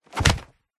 Звуки ударов, пощечин
Удар телом борьба захват блок бросок 2